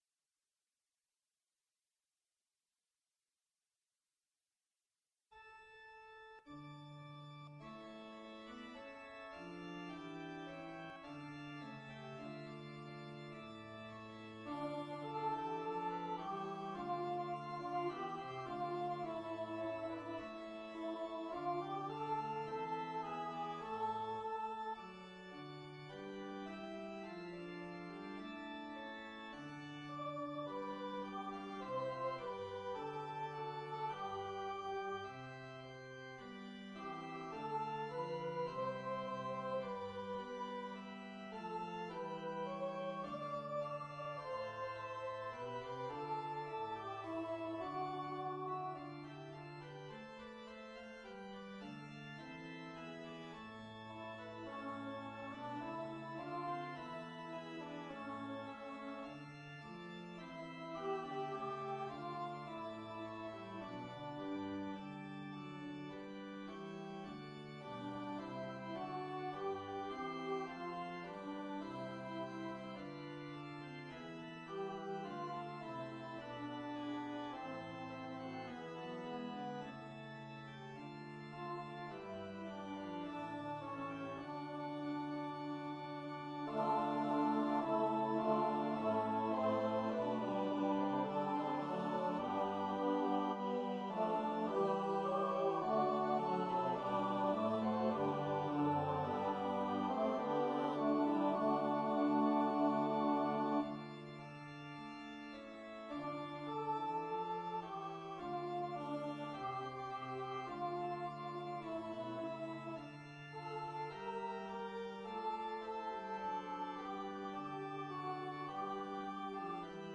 EARLY-SEVENTEENTH-CENTURY ENGLISH SACRED MUSIC
Voices:SAT soli, SAATB chorus & organ